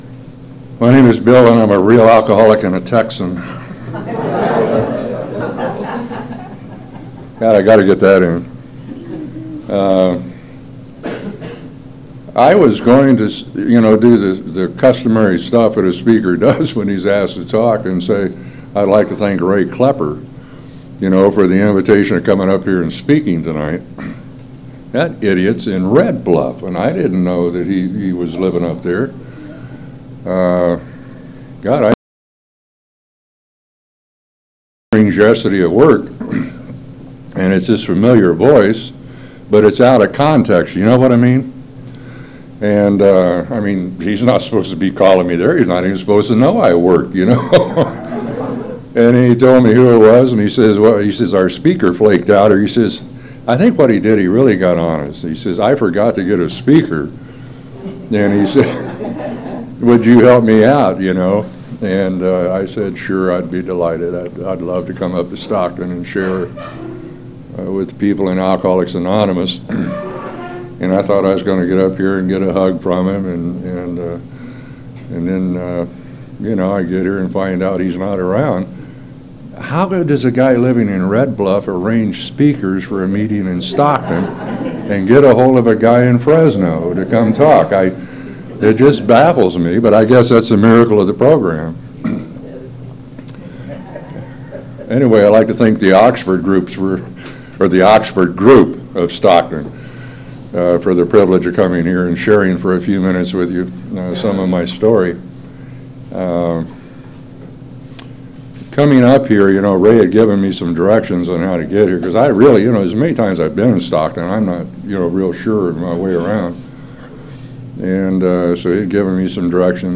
Oxford Group Speaker Meeting - Stockton CA